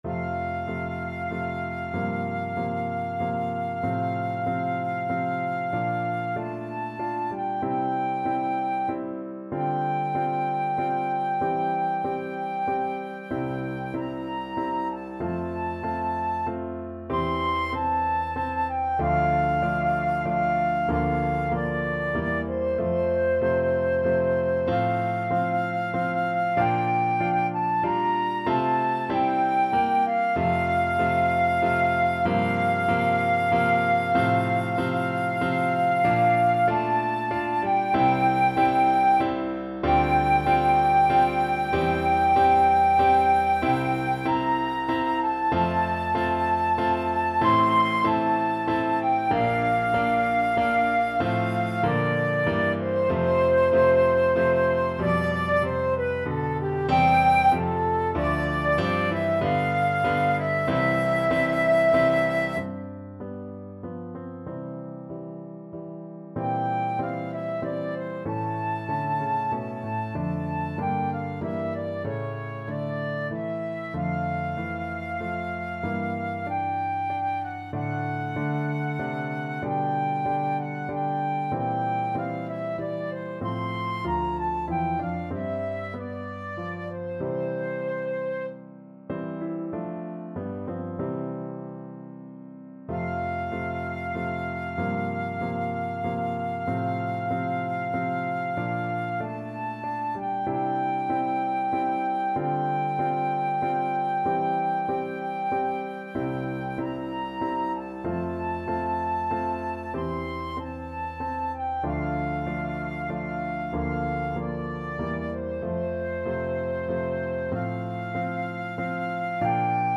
Classical Handel, George Frideric Thanks Be to Thee (Dank sei dir Herr) Flute version
F major (Sounding Pitch) (View more F major Music for Flute )
=95 Andante
3/4 (View more 3/4 Music)
Classical (View more Classical Flute Music)